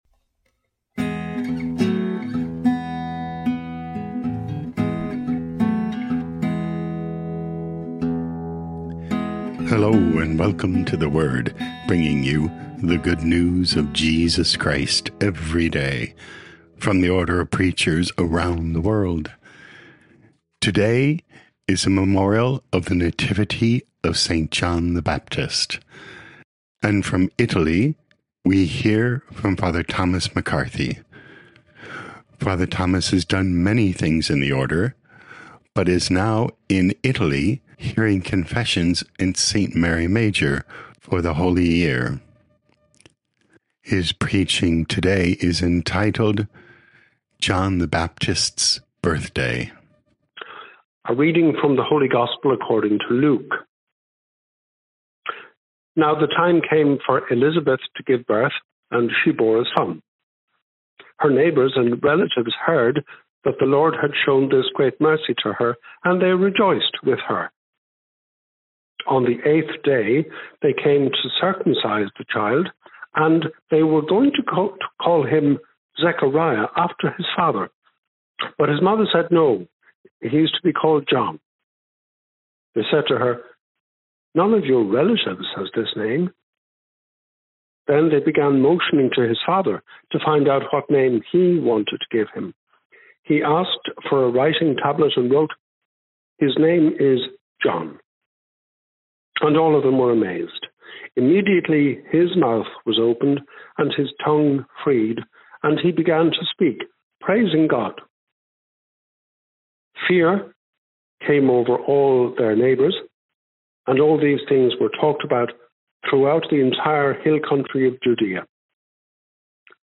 24 Jun 2025 John the Baptist’s Birthday Podcast: Play in new window | Download For 24 June 2025, The Nativity of Saint John the Baptist, based on Luke 1:57-66, 80, sent in from Rome, Italy.